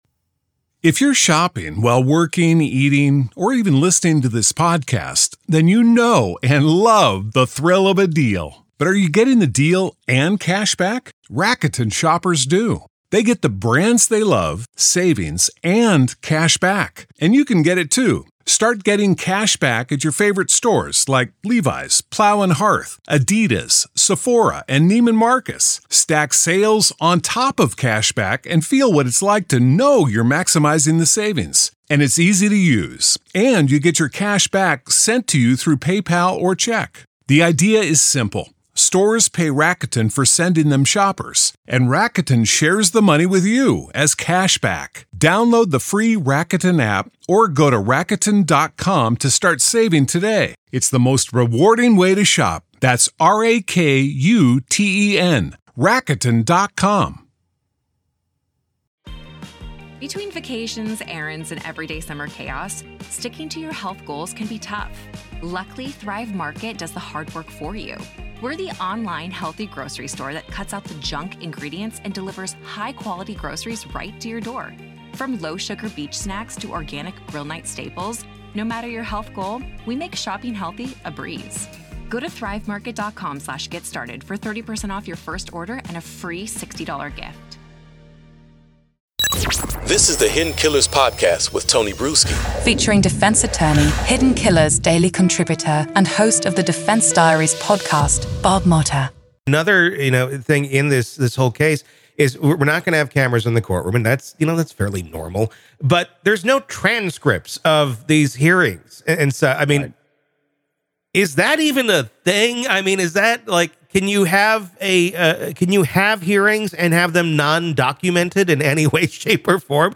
This conversation sheds light on the intricate legal maneuvers and challenges faced by both sides of the case.